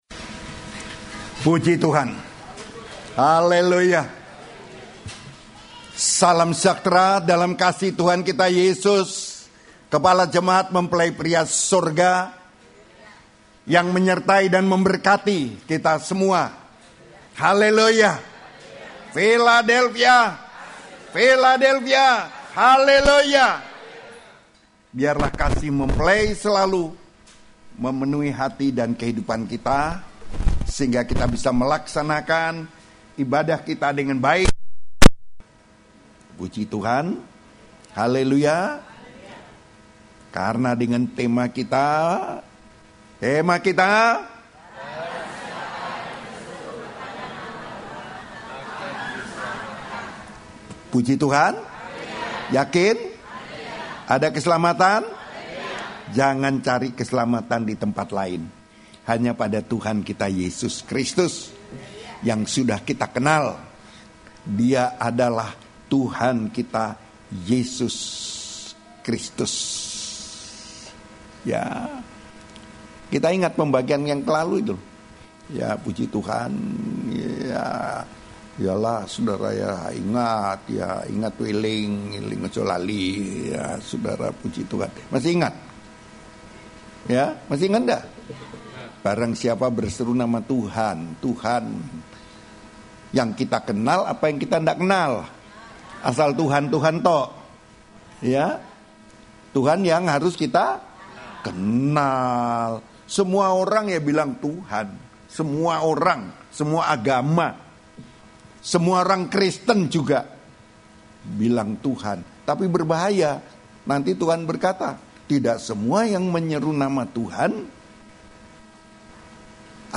Khotbah (Audio)
Khotbah Pengajaran